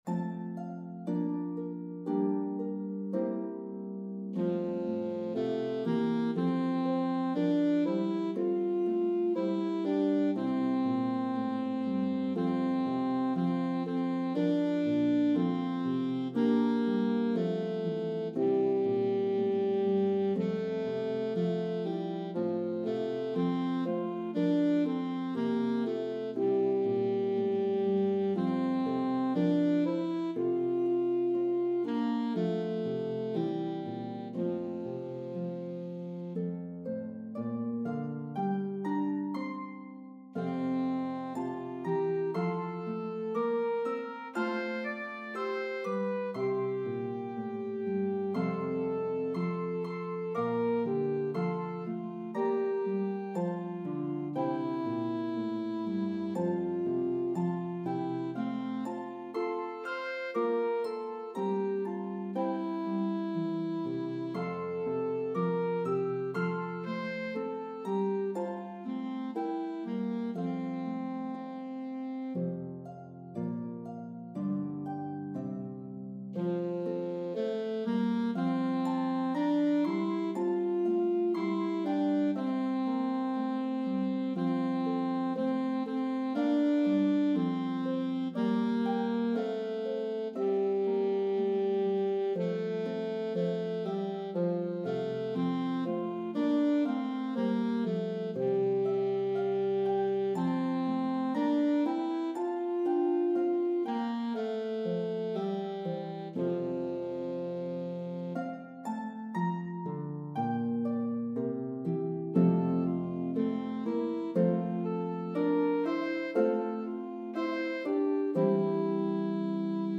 Playable on Lever or Pedal Harps.